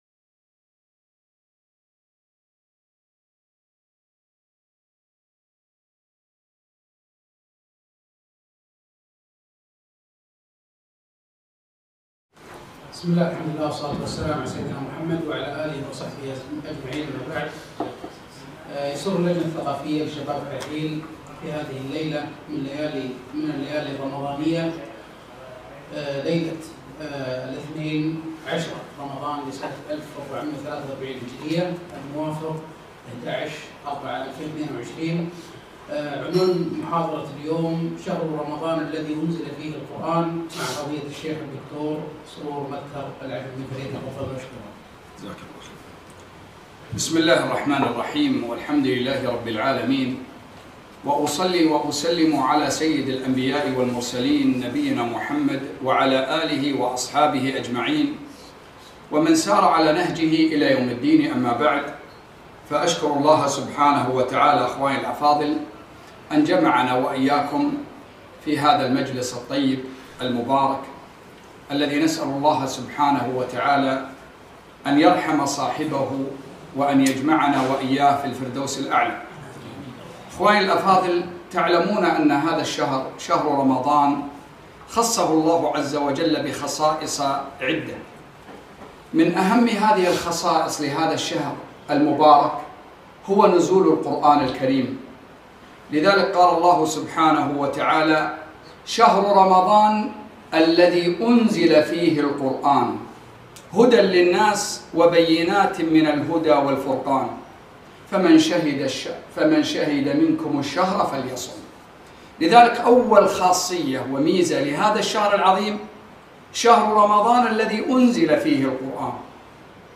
محاضرة - شهر رمضان الذي أنزل فيه القرآن